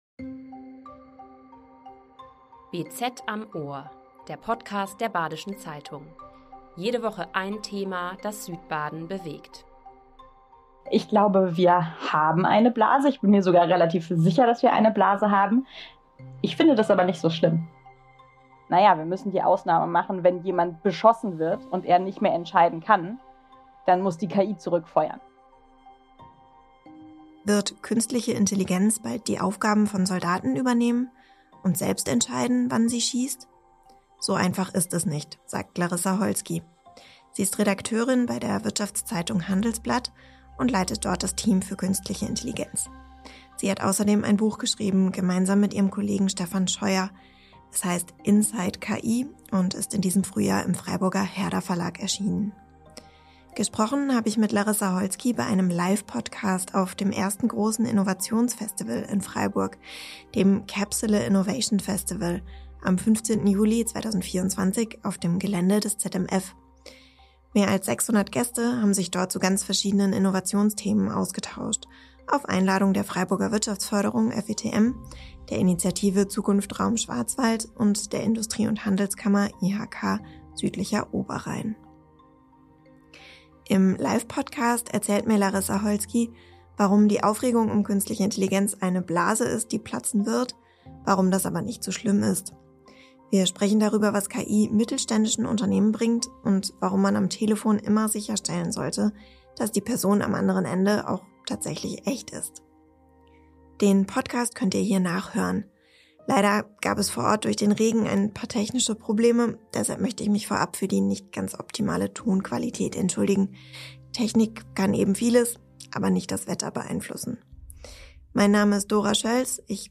Leider gab es vor Ort durch den Regen ein paar technische Probleme – deshalb möchten wir uns für die nicht optimale Tonqualität entschuldigen.